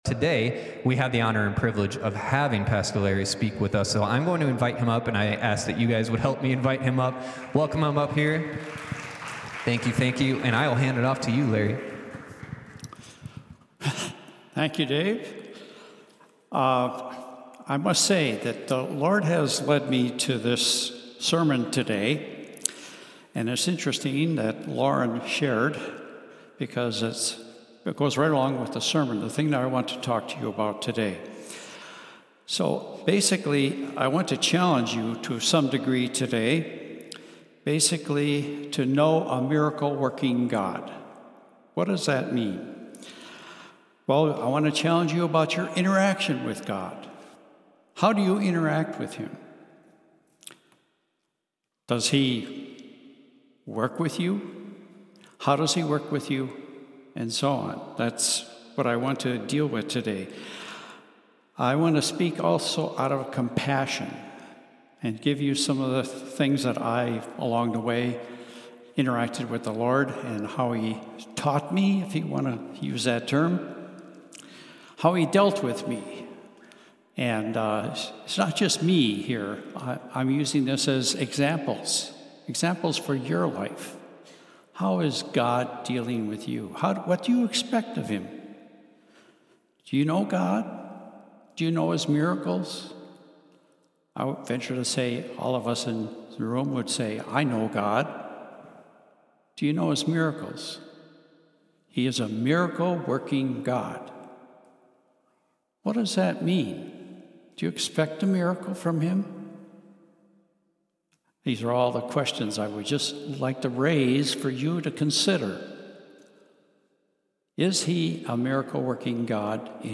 Enjoy these stand-alone messages from Dwelling House Church.